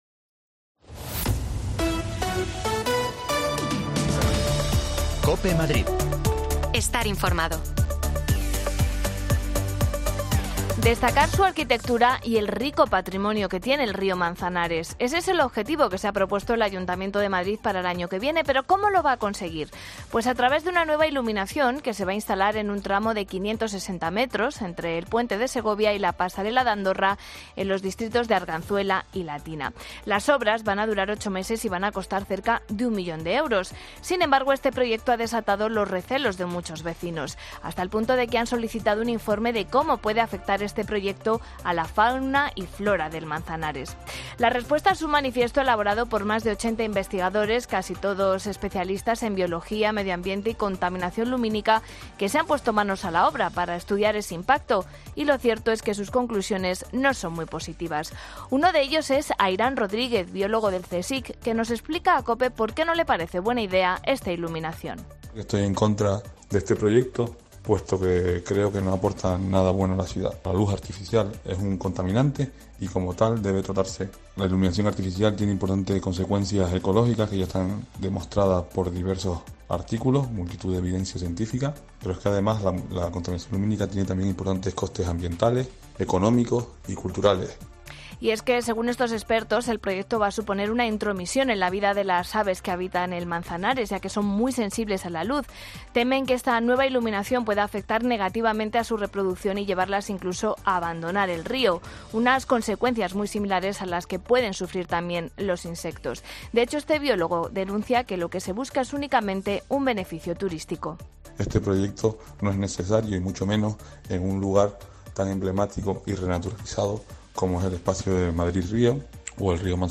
Las desconexiones locales de Madrid son espacios de 10 minutos de duración que